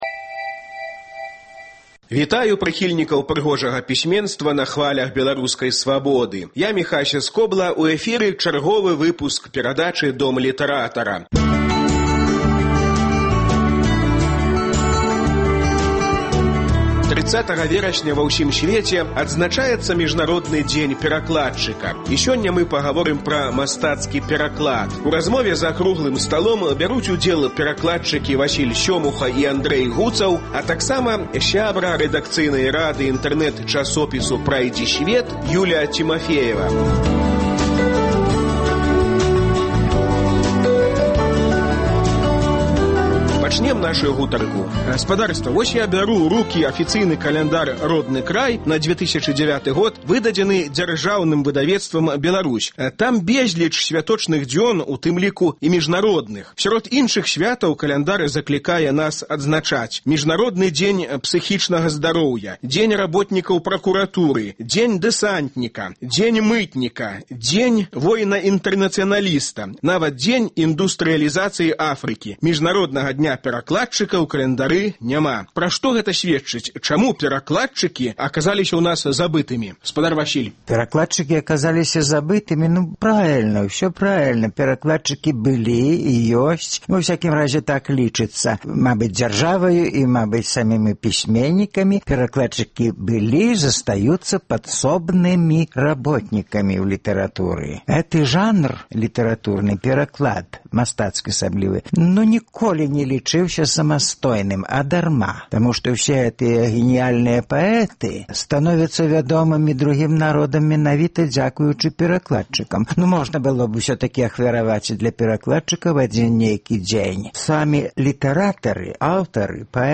30 верасьня ва ўсім сьвеце адзначаецца Міжнародны дзень перакладчыка. З гэтай нагоды ў "Доме літаратара" круглы стол пра мастацкі пераклад.